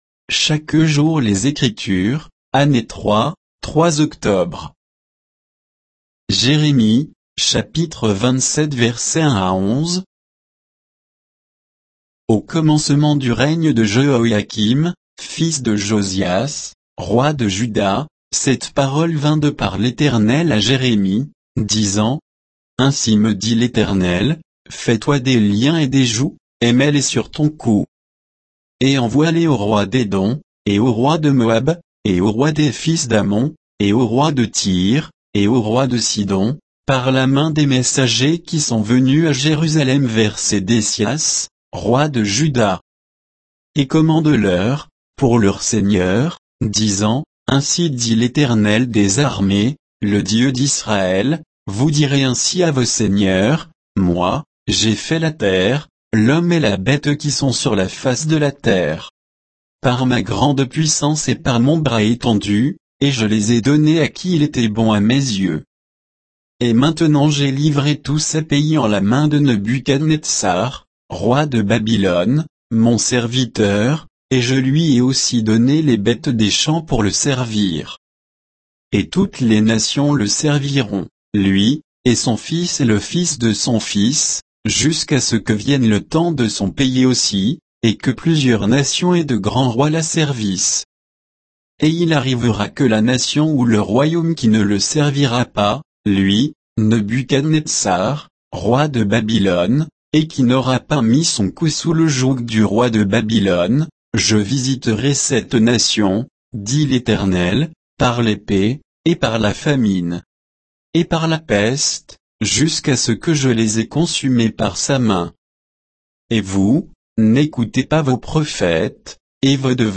Méditation quoditienne de Chaque jour les Écritures sur Jérémie 27, 1 à 11